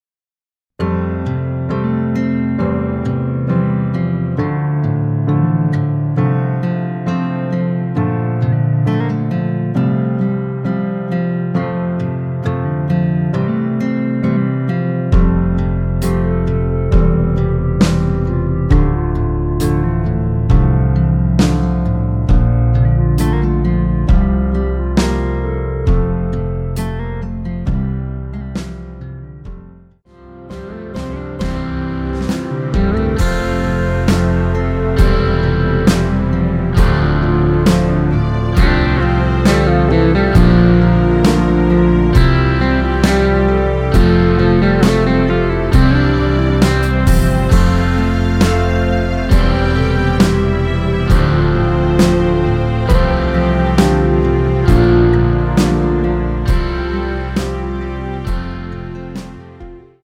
원키에서(-3)내린 멜로디 포함된 MR입니다.(미리듣기 참조)
앞부분30초, 뒷부분30초씩 편집해서 올려 드리고 있습니다.
중간에 음이 끈어지고 다시 나오는 이유는